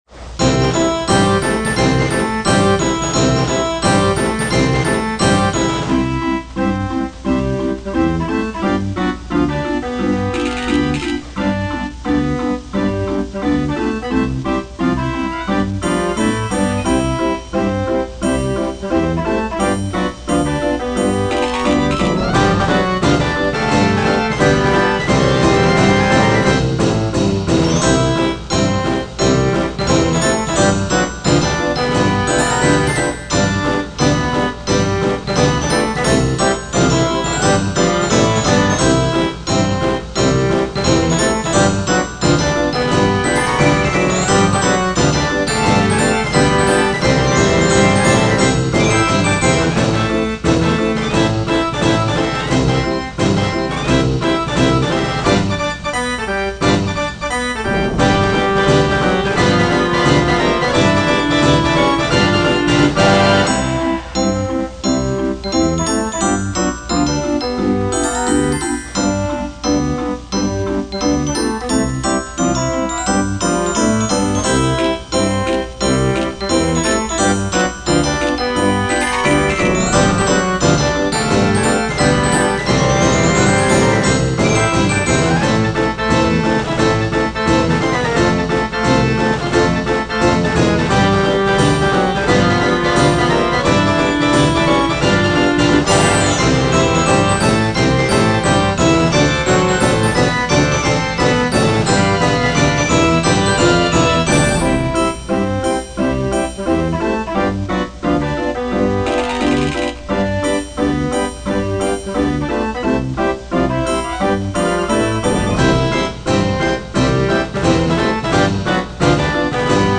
Boogie/FT